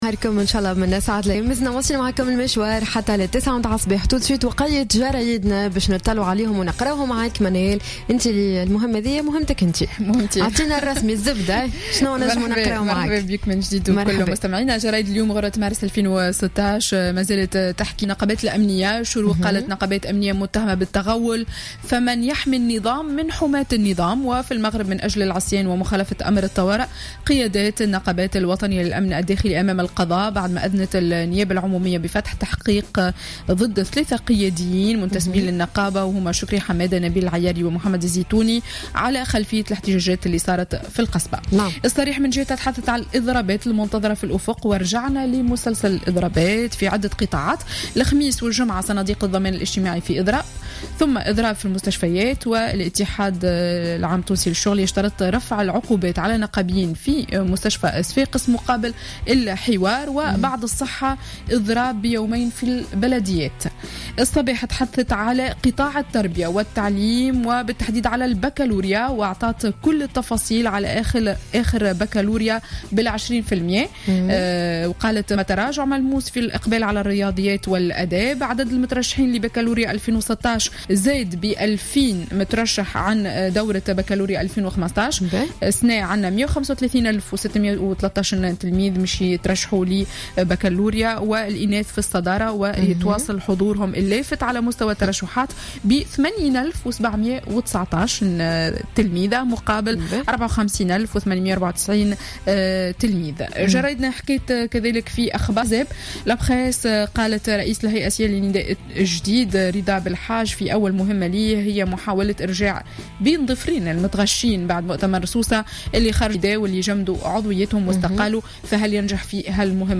معرض الصحافة ليوم الثلاثاء 1 مارس 2016